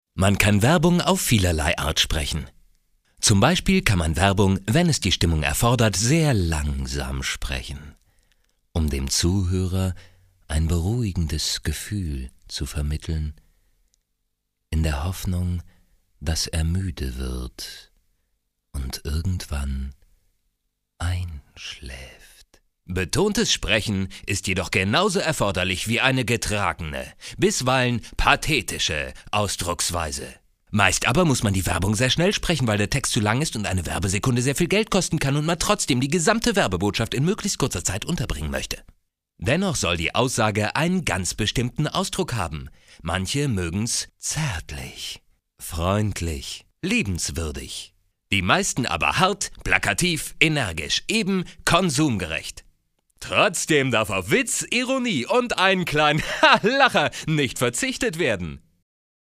deutscher Sprecher für hörspiel, synchron, doku, voice-over, industrie, werbung, feature etc.
Sprechprobe: Werbung (Muttersprache):